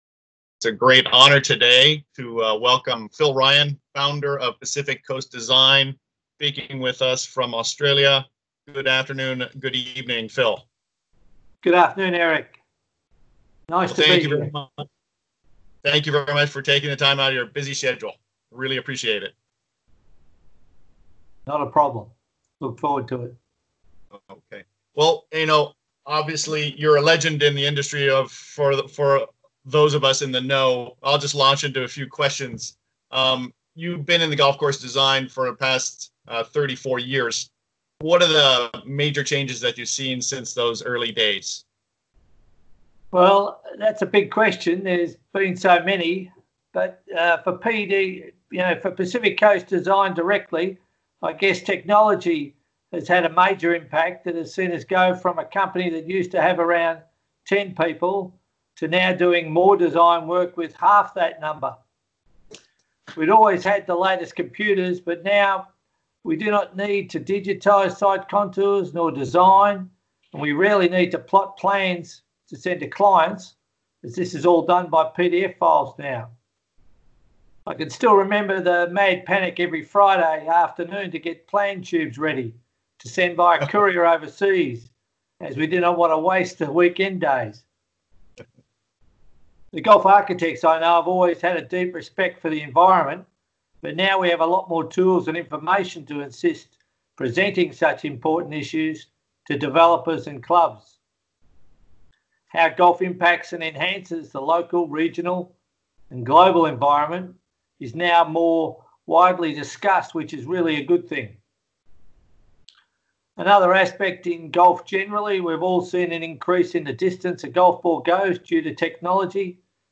ASIA: Podcast interview